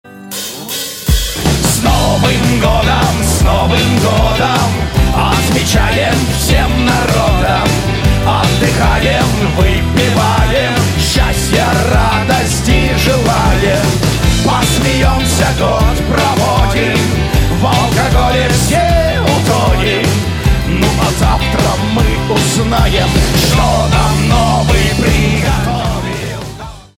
# Новогодние Рингтоны
# Поп Рингтоны